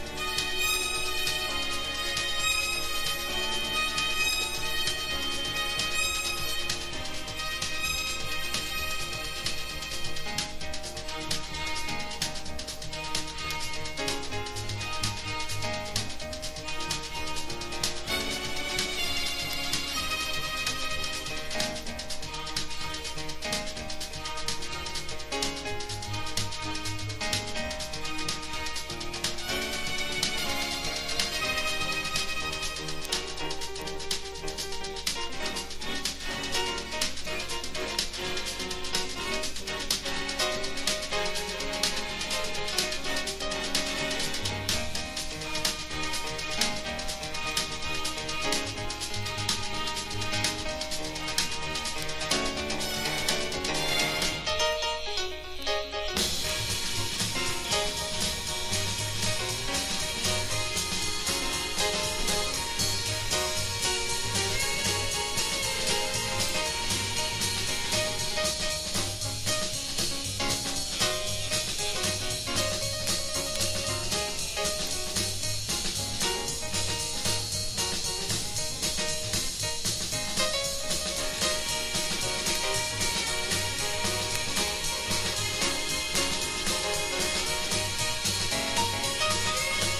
VOCAL JAZZ# BIGBAND / SWING
(USステレオ盤 ゲートフォールド仕様 ST-20166)